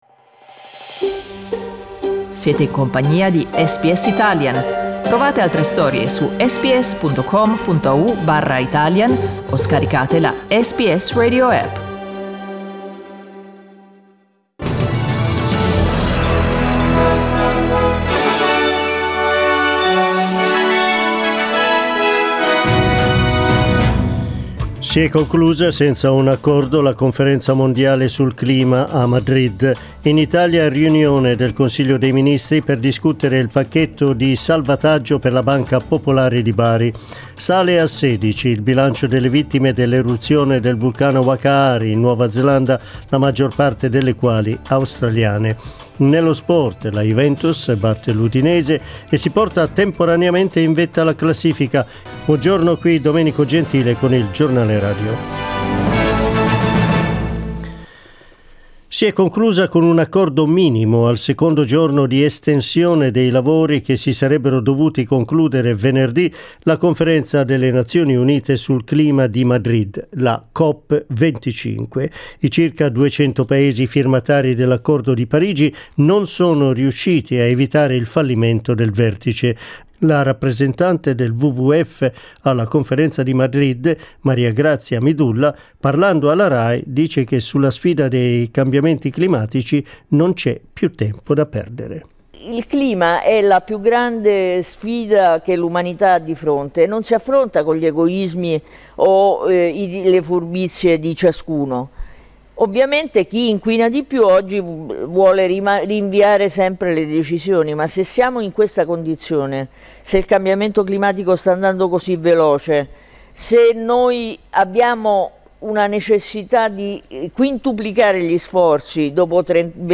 Our news bulletin (in Italian). ur news bulletin (in Italian).